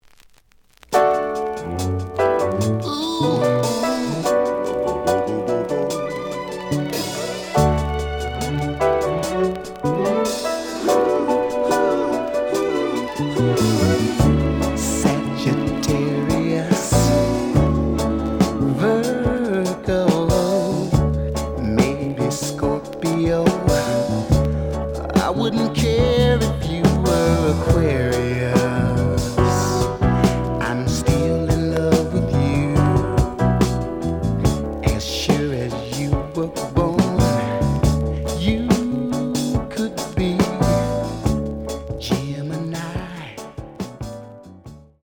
試聴は実際のレコードから録音しています。
●Genre: Soul, 70's Soul
●Record Grading: VG (両面のラベルに若干のダメージ。盤に若干の歪み。傷は多いが、プレイはまずまず。)